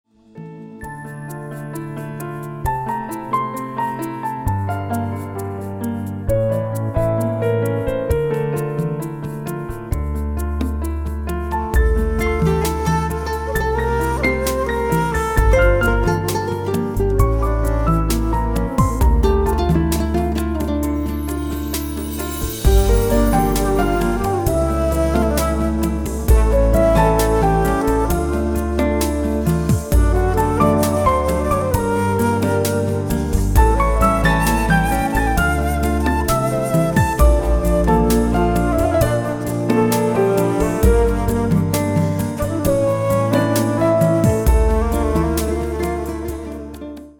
• Качество: 224, Stereo
грустные
спокойные
инструментальные
New Age
тоска
Грустная и в то же время романтичная мелодия